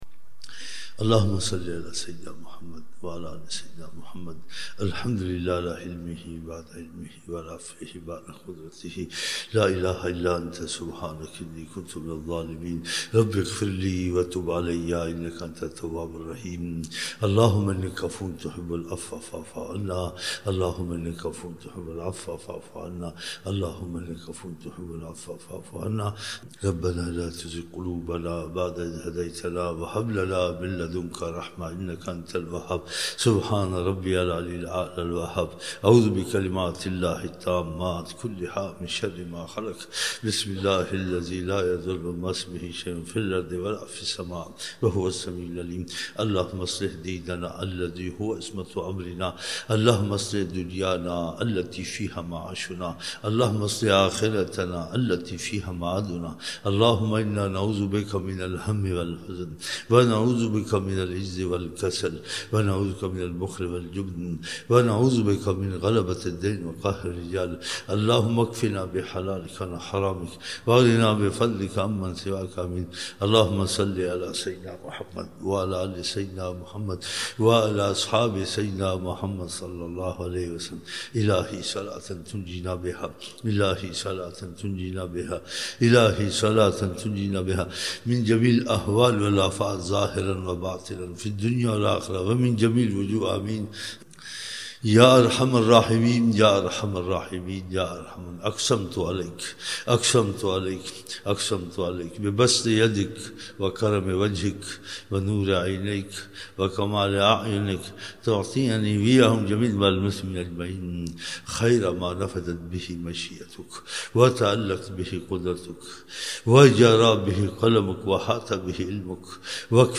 11 November 1999 - Thursday Zohar dua (3 Shaban 1420)
ظہر محفل
Dua mubarak - 19 minutes